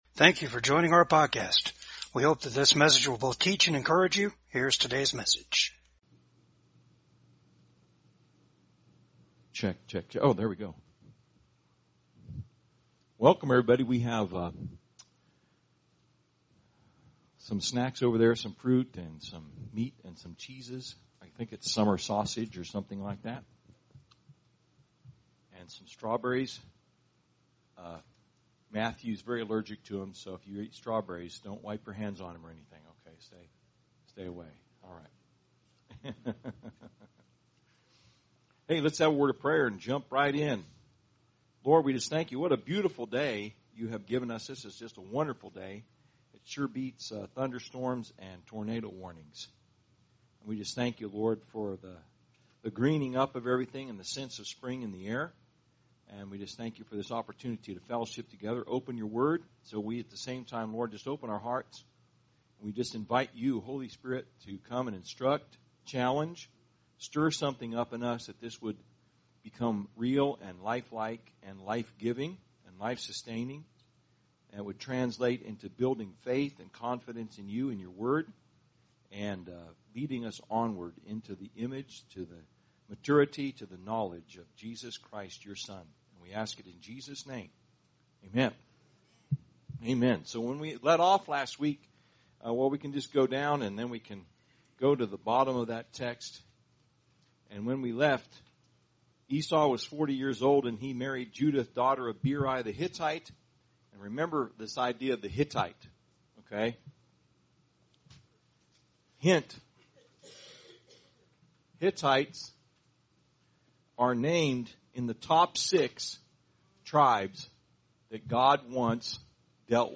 TRUTH SEEKERS Service Type: TRUTH SEEKERS TUESDAYS THE 23RD SESSION OF TRUTH SEEKERS